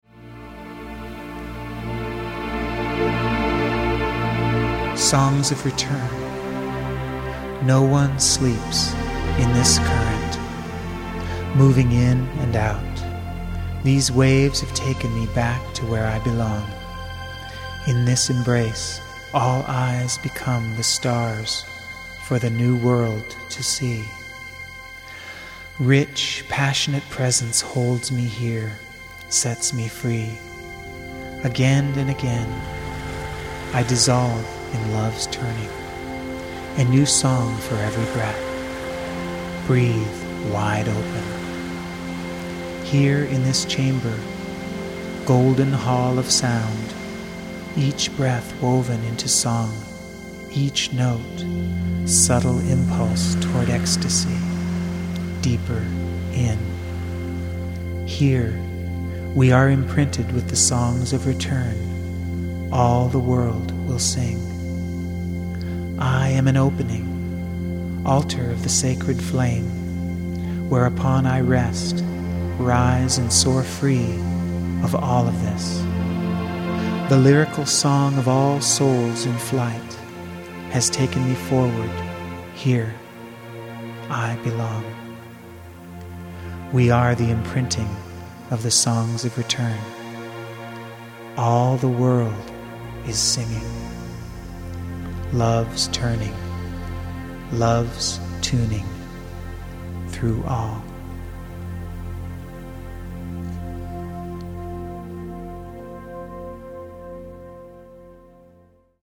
Ecstatic Poetry & Music for the Lover in All...